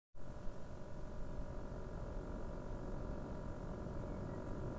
Hier noch ne Aufnahme vom rattern am Whisper M. Alle 10ms ratterts das passt halt genau auf 600 rpm. Beim Enermax hört sichs nicht anders an nur leiser..